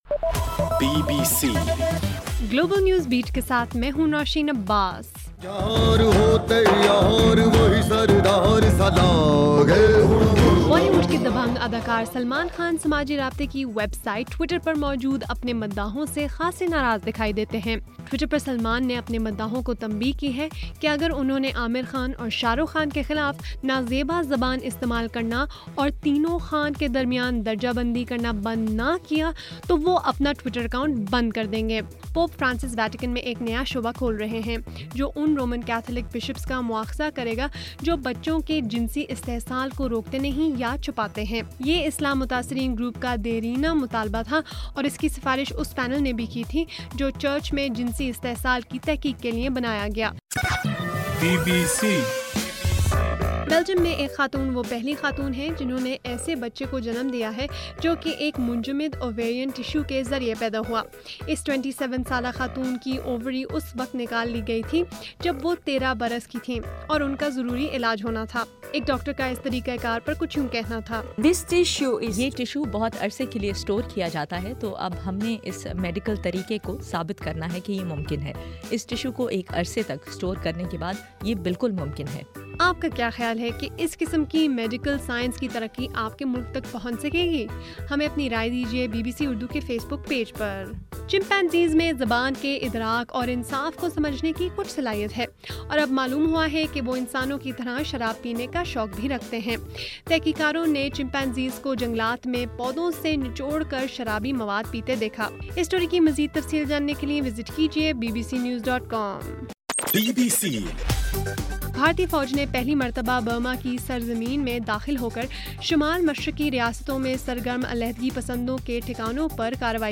جون 10: رات 10بجے کا گلوبل نیوز بیٹ بُلیٹن